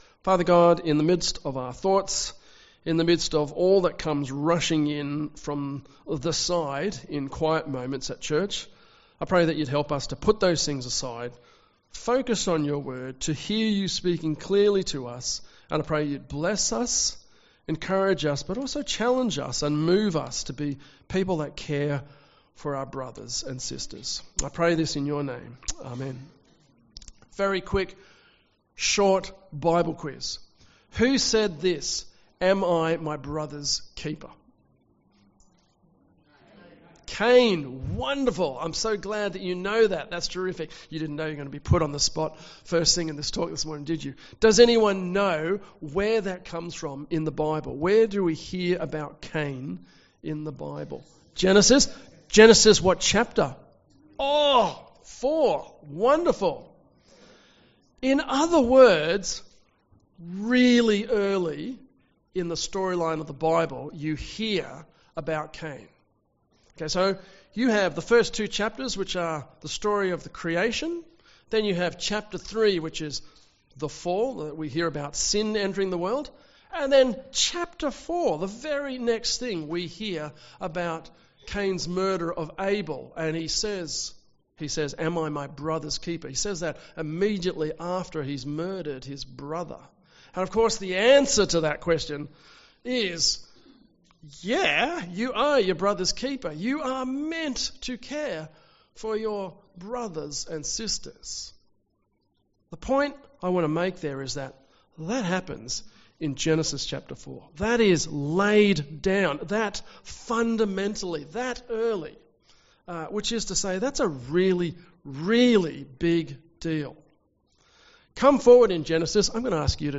Western Blacktown Presbyterian Church is now Hope at the Hill meeting at Rooty Hill.
Sermon